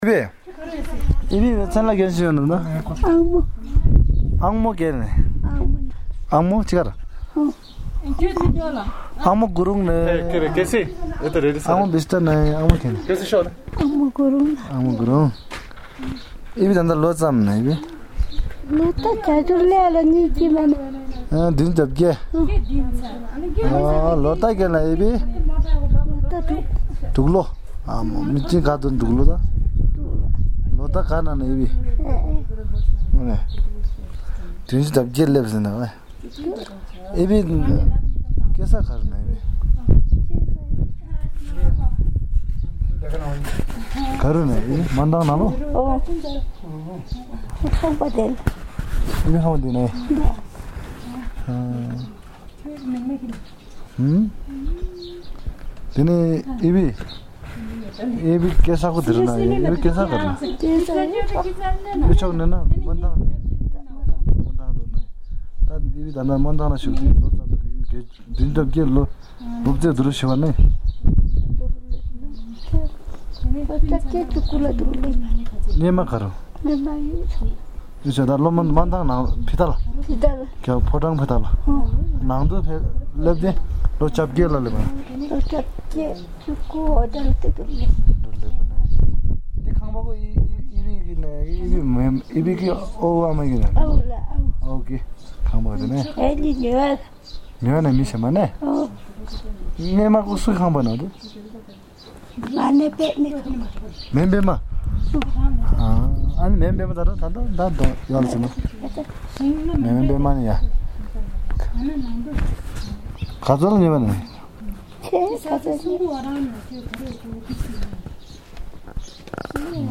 Interview of a community member on the 2015 Nepal Earthquakes
Audio Interview